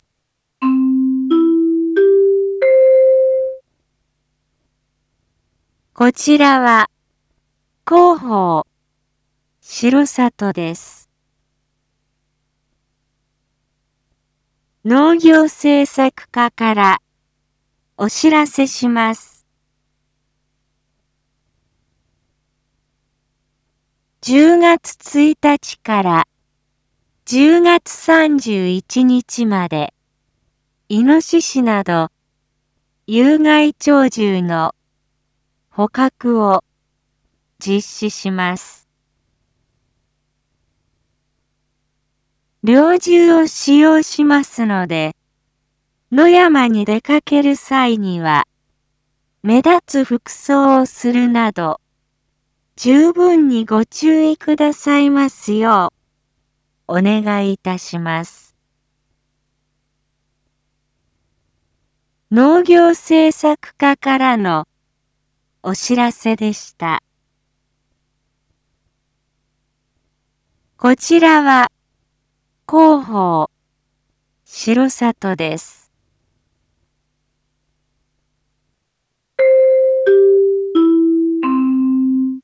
一般放送情報
Back Home 一般放送情報 音声放送 再生 一般放送情報 登録日時：2023-10-09 19:01:24 タイトル：有害鳥獣捕獲について インフォメーション：こちらは、広報しろさとです。